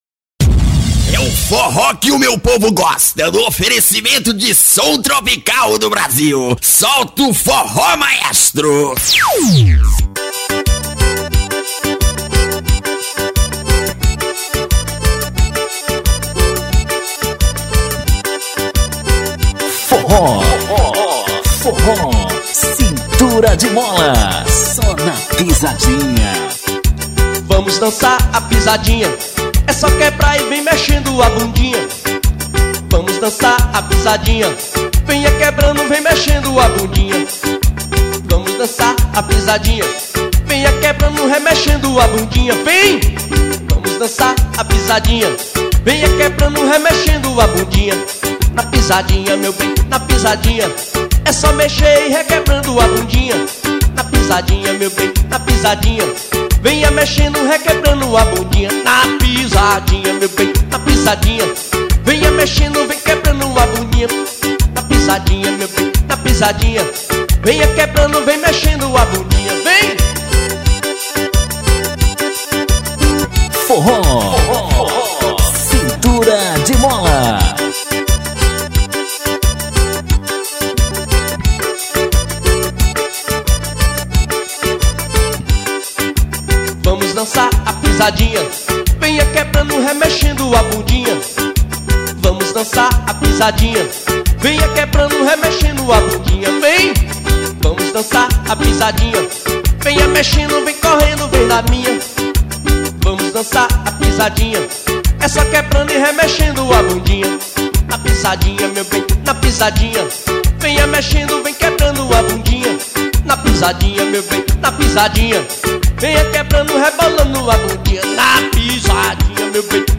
Axes Forros Para Ouvir: Clik na Musica.